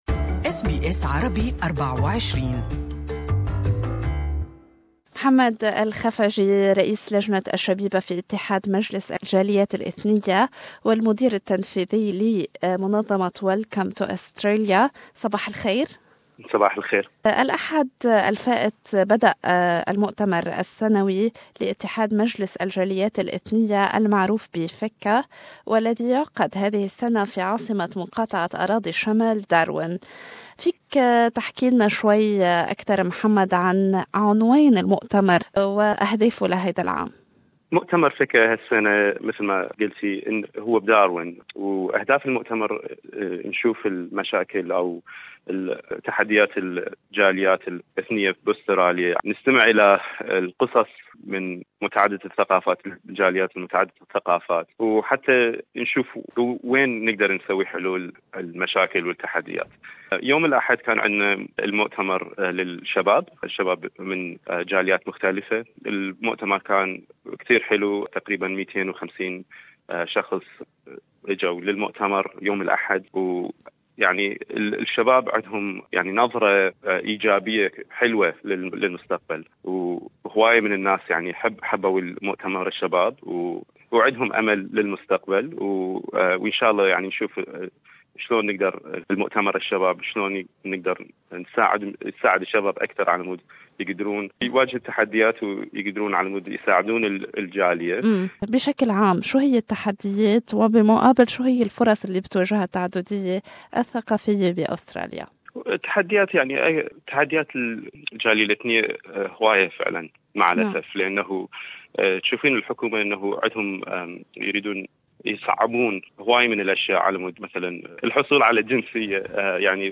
للإستماع إلى اللقاء الكامل أنقر على التدوين الصوتي أعلاه استمعوا هنا الى البث المباشر لاذاعتنا و لاذاعة BBC أيضا شارك